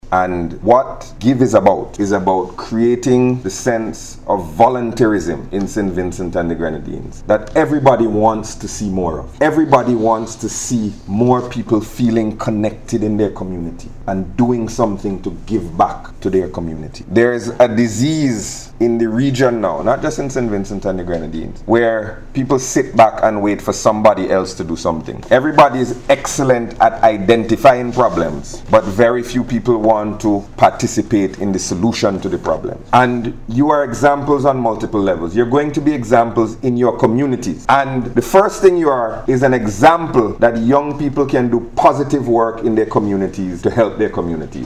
Minister Gonsalves was speaking at the official equipment handover ceremony held at the Ministry of Foreign Affairs Conference Room on Tuesday, where the first five community-based youth groups received resources to help bring their volunteer projects to life.